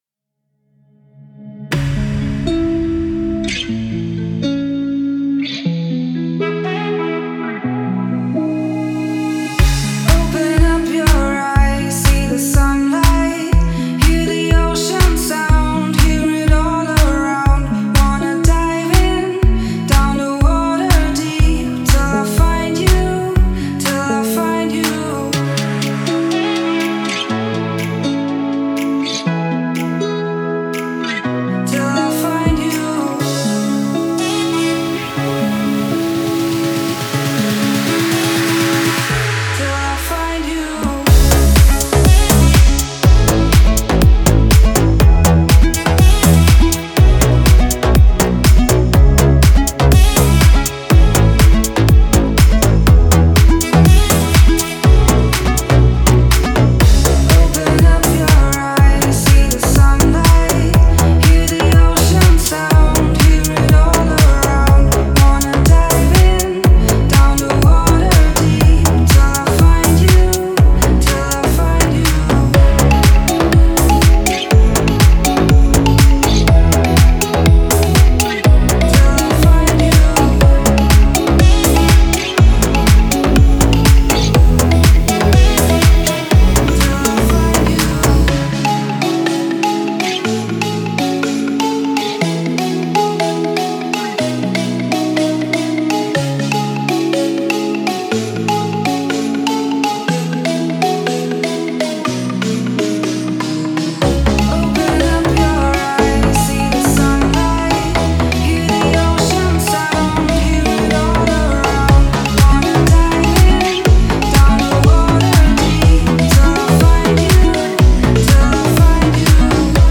это атмосферная композиция в жанре электронной музыки